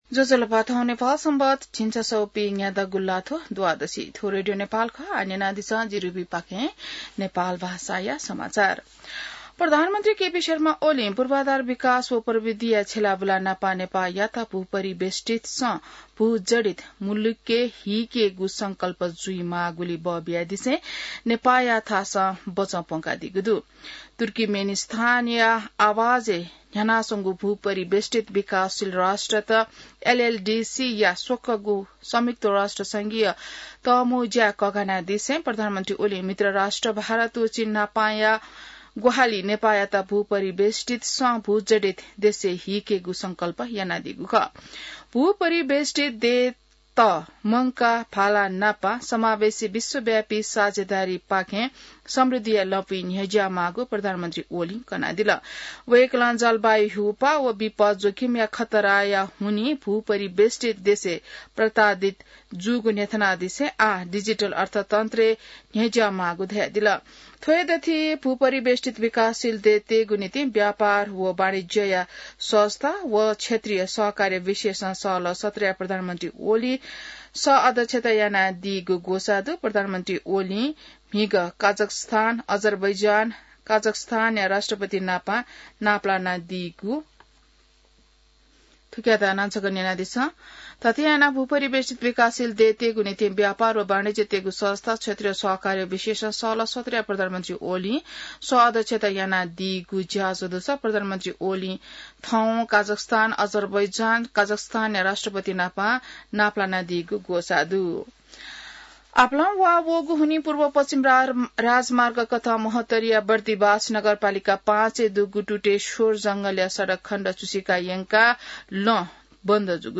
नेपाल भाषामा समाचार : २१ साउन , २०८२